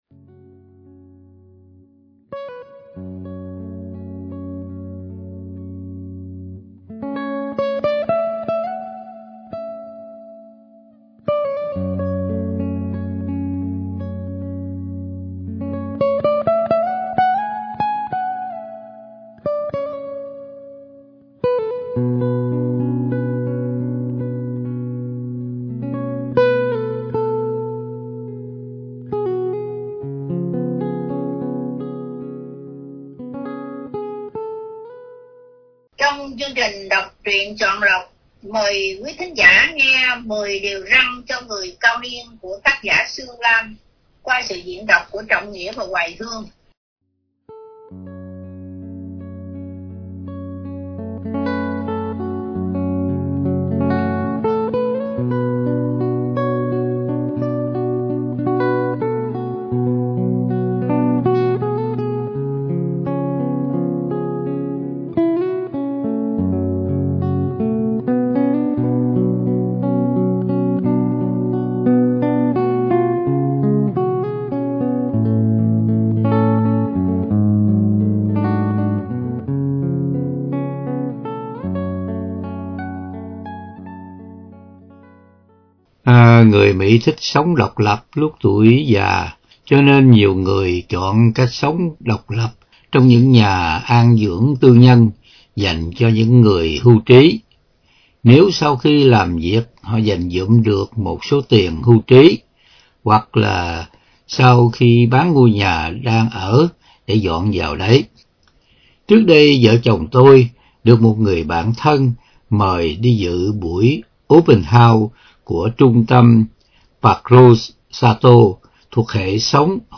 Đọc Truyện Chọn Lọc ‘ 10 Diều Răn Cho Người Cao Niên ”